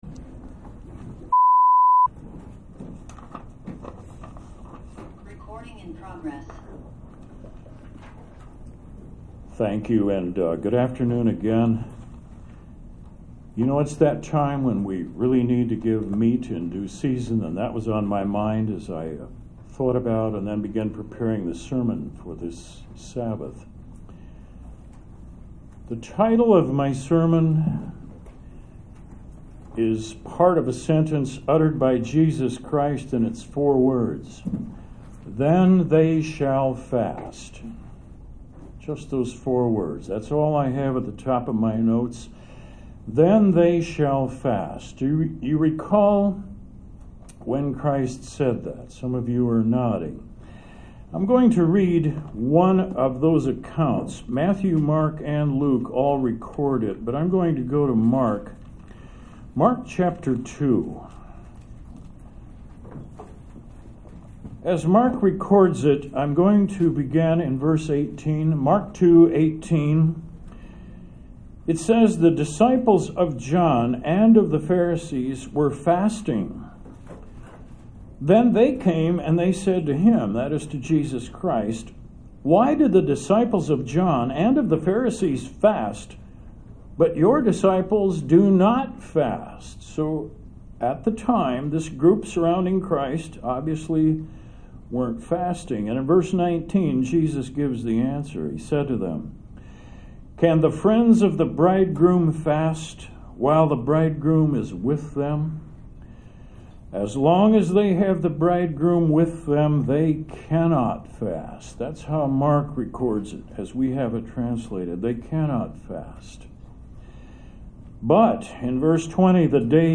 This sermon delves into the matter of fasting to help prepare to observe the Day of Atonement. Apology for a couple of glitches in the video due to meeting in a cramped hall.